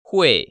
[huì]
후이